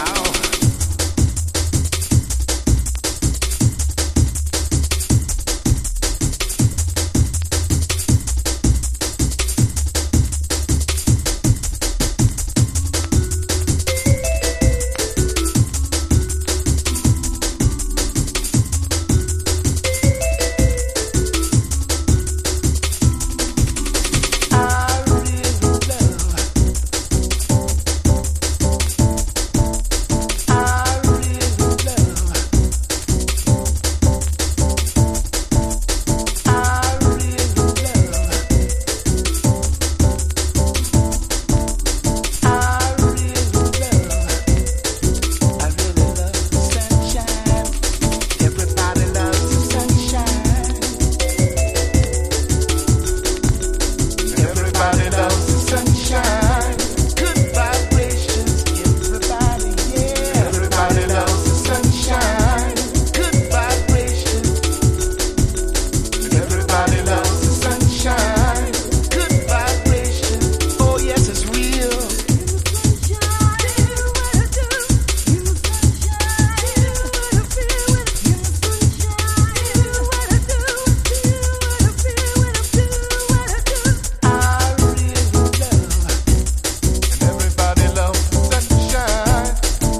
メロウかつファンキーで最高!!
FUNK / DEEP FUNK